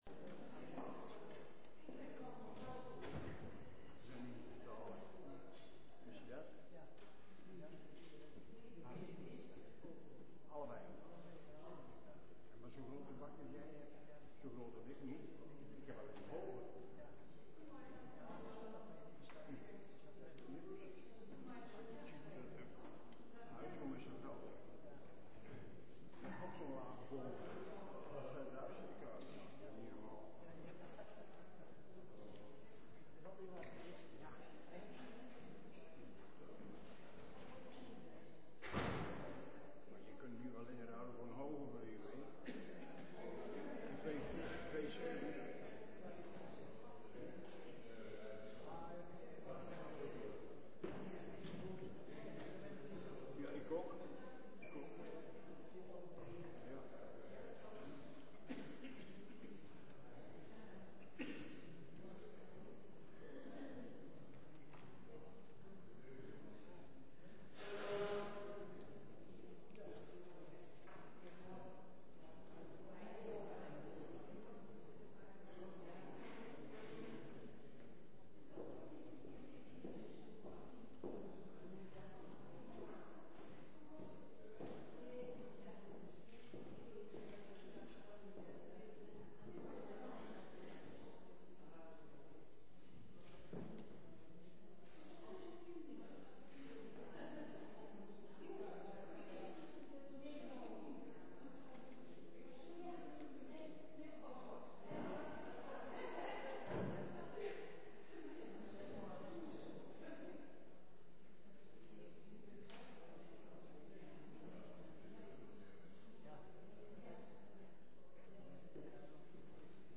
Op zondag 18 september was onze start voor het nieuwe seizoen.
Dienst luisteren